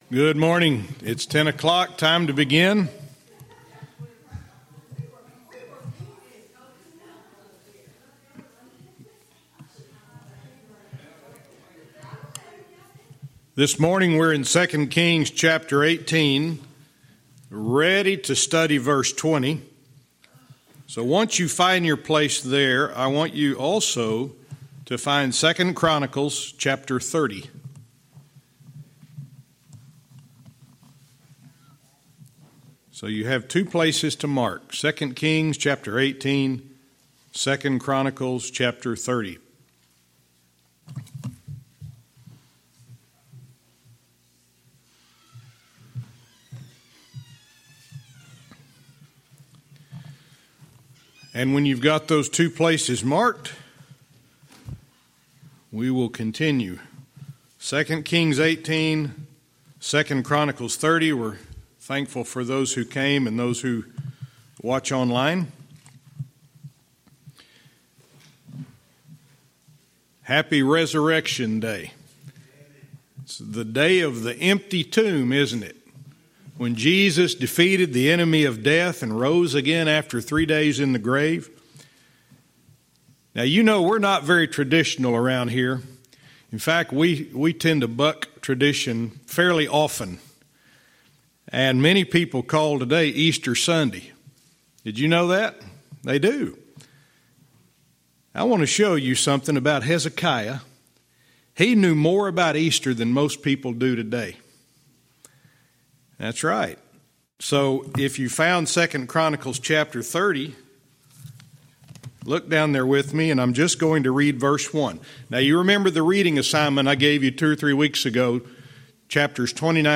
Verse by verse teaching - 2 Kings 18:20-22